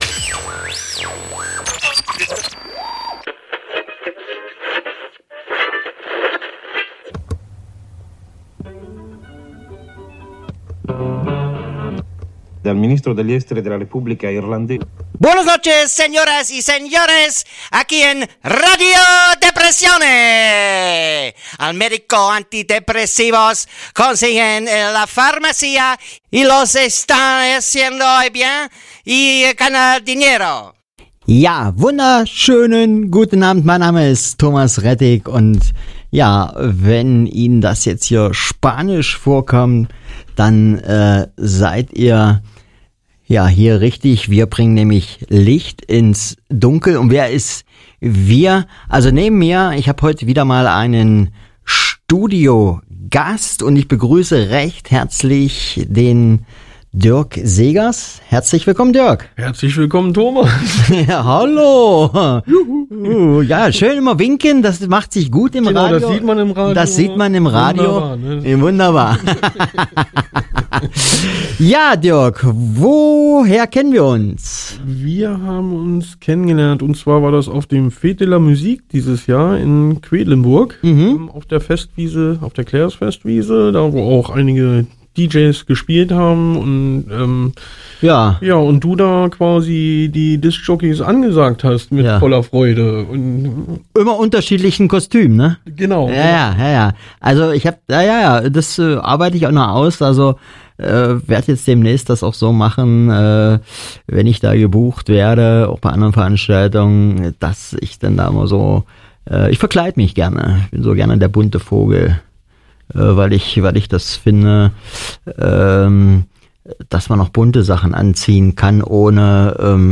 Es geht um psychische Störungen und Erkrankungen, Behandlungsmöglichkeiten und Anlaufpunkte für Betroffene. Dazu gibt es regelmäßig Interviews mit Fachleuten und Betroffenen, Buchtipps und Umfragen zu bestimmten Themen.